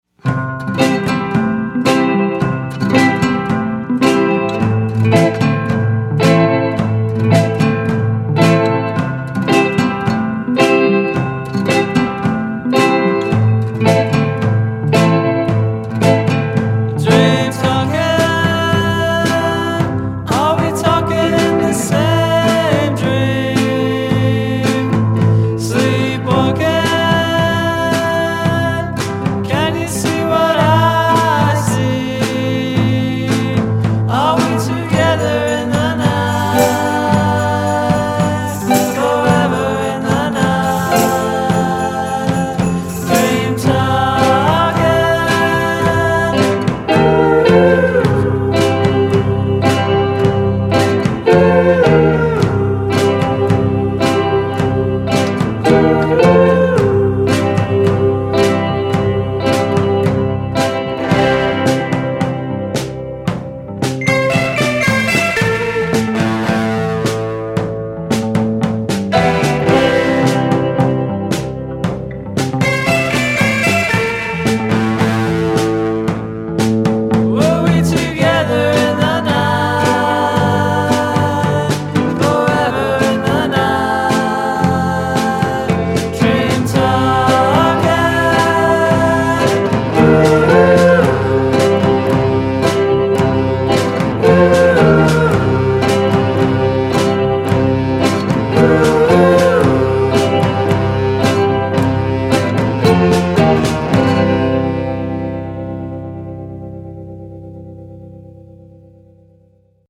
play easy-breezy, Beach Boys tinged psychedelic tunes